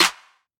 Snare (Metro).wav